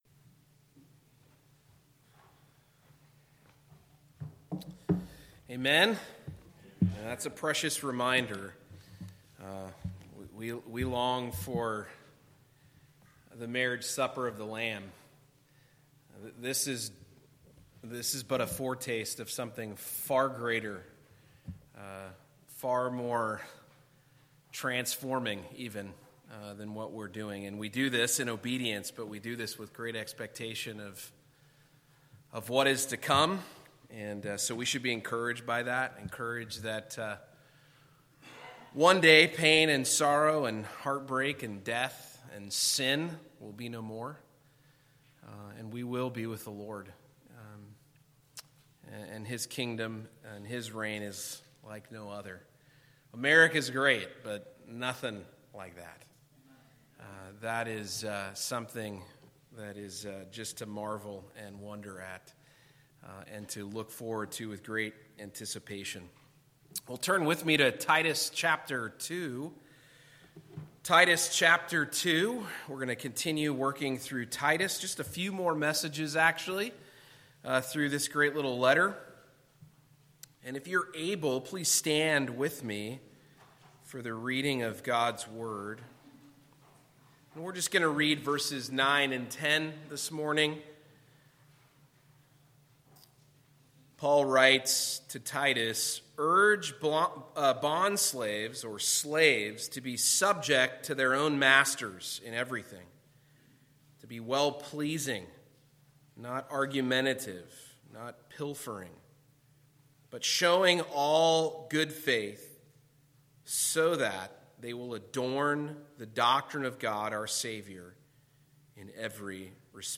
Passage: Titus 2:9-10 Service Type: Sunday Morning